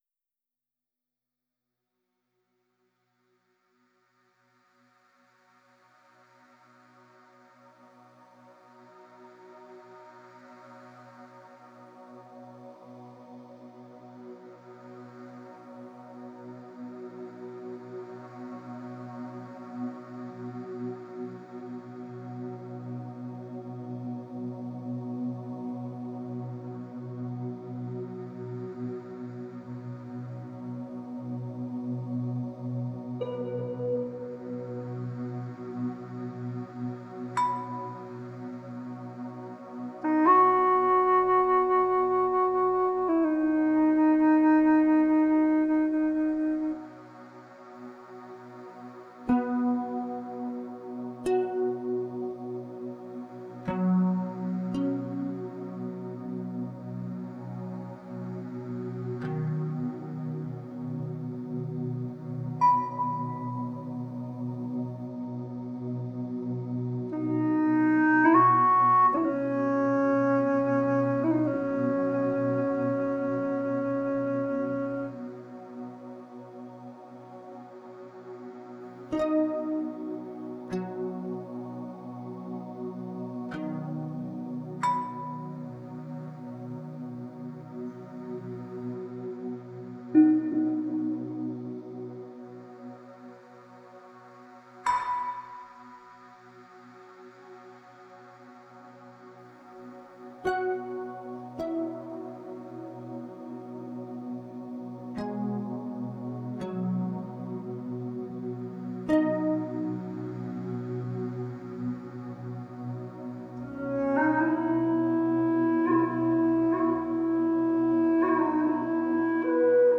A special kind of music for prematurely born babies